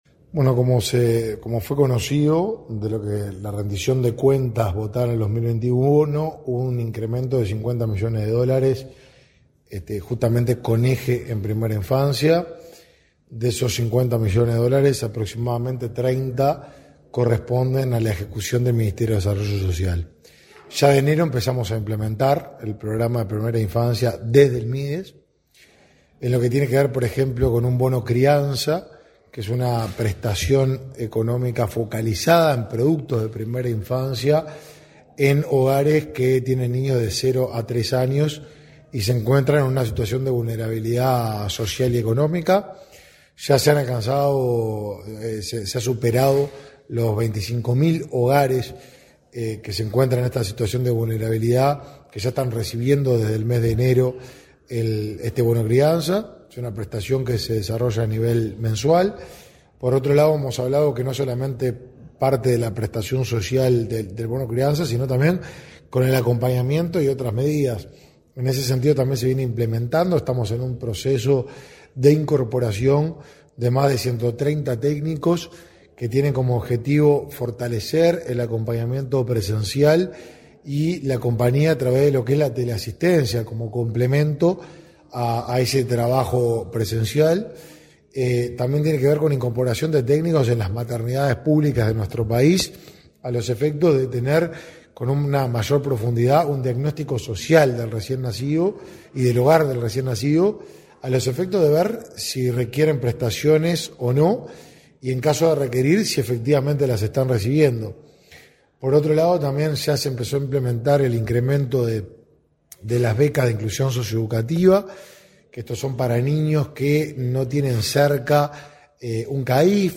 Entrevista al ministro de Desarrollo Social, Martín Lema
Lema entrevista.mp3